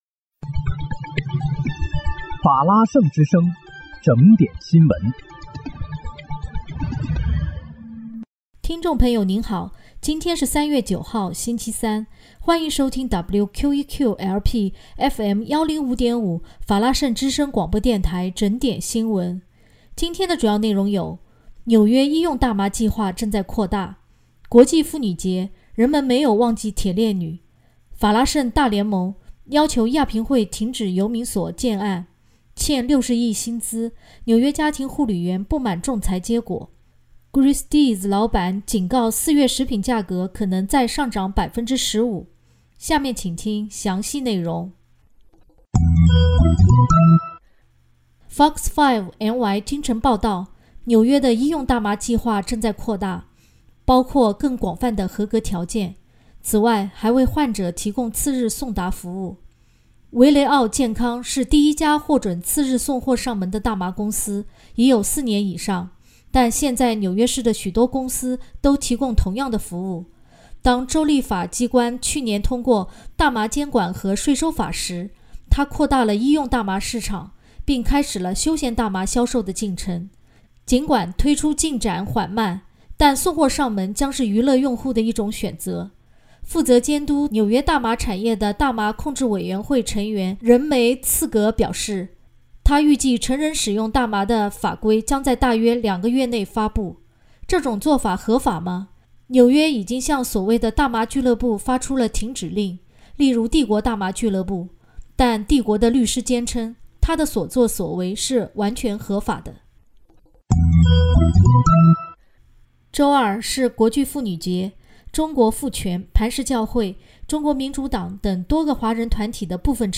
3月9日（星期三）纽约整点新闻